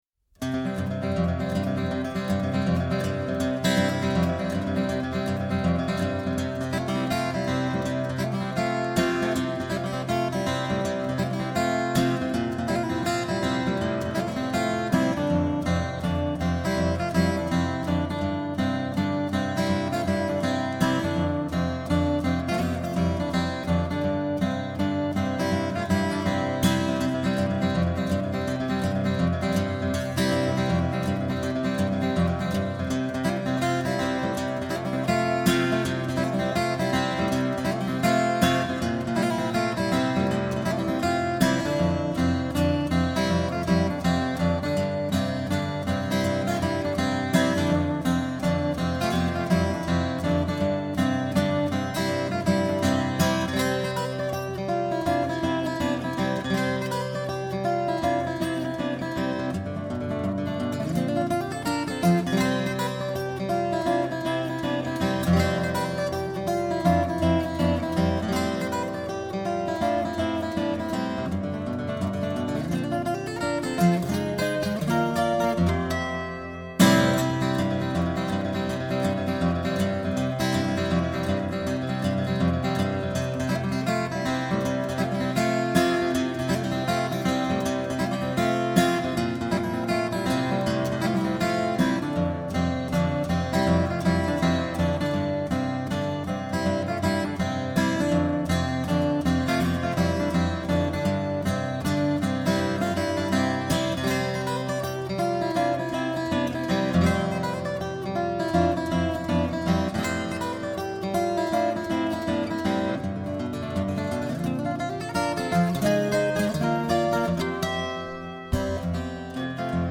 1. The guitar.
Tags1980s 1989 Canada Folk